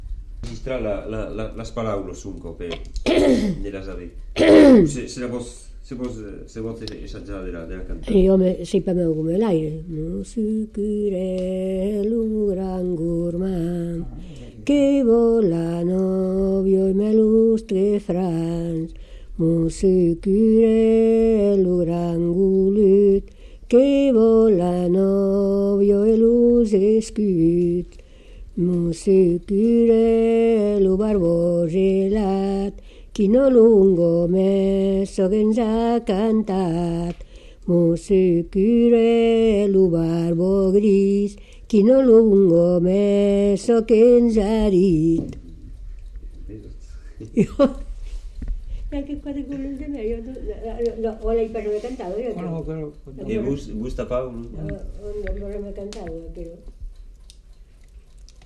Lieu : Moncrabeau
Genre : chant
Effectif : 1
Type de voix : voix de femme
Production du son : chanté